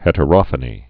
(hĕtə-rŏfə-nē)